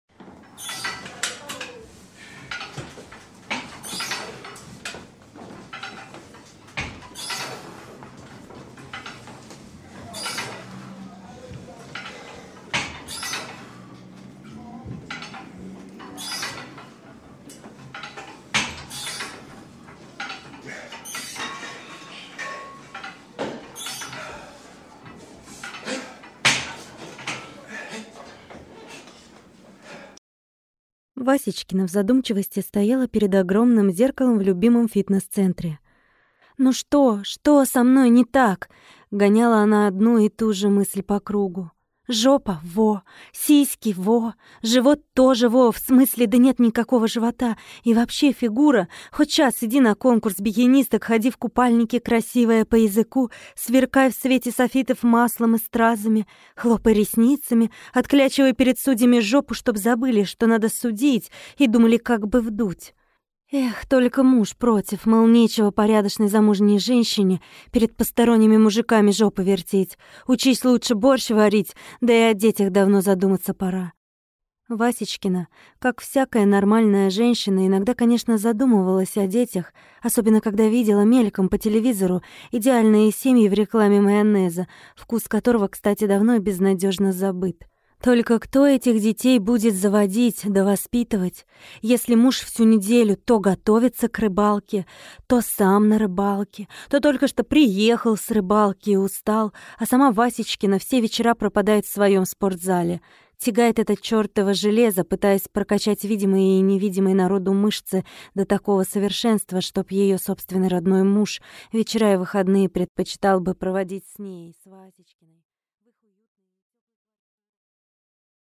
Аудиокнига Блёсны | Библиотека аудиокниг